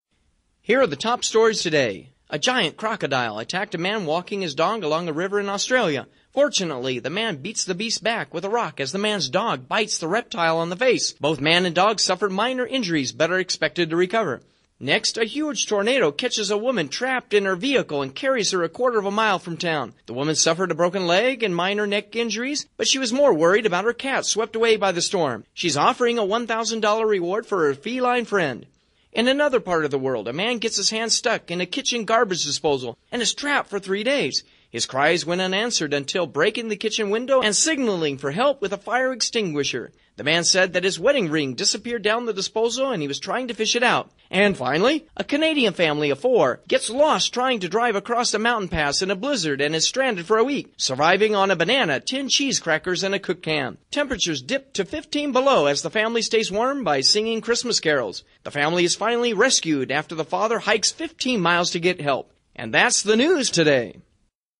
World-News-Stories-1.mp3